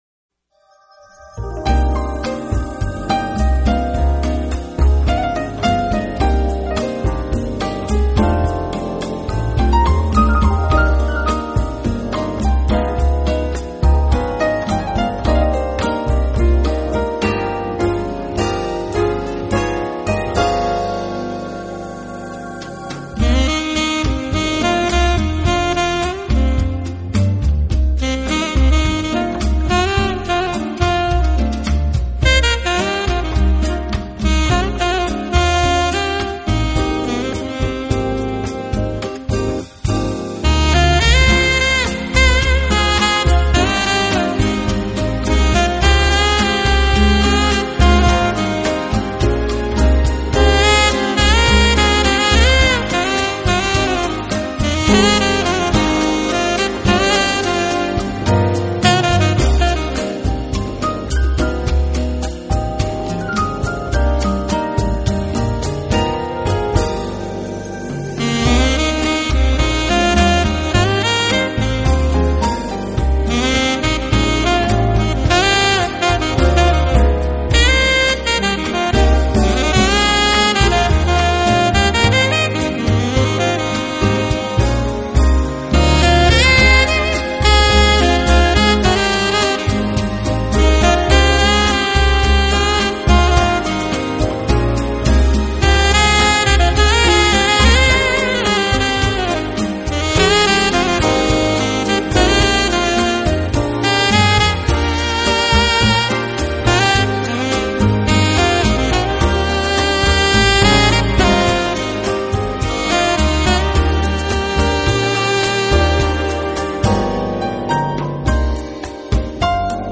也不例外，大量的温柔而舒缓的曲子，非常适合夜晚来聆听。
这张，就是典型的Smooth Jazz音乐